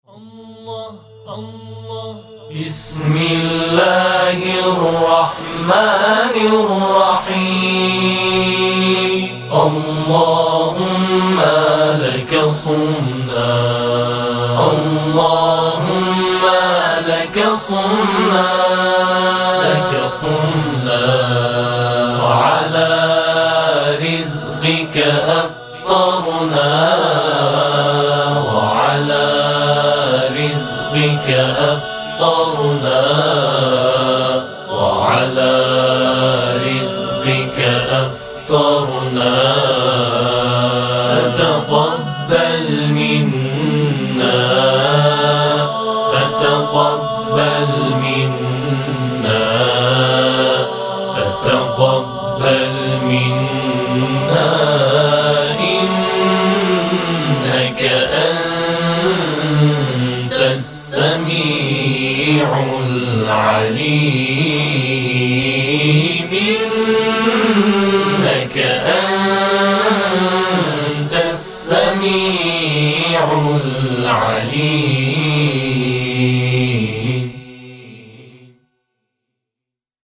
همخوانی دعای افطار | گروه تواشیح بین المللی تسنیم
گروه تواشیح بین المللی تسنیم، در بهمن ماه سال 1392 توسط جمعی از قاریان قرآن نوجوان اصفهان پایه گذاری شد.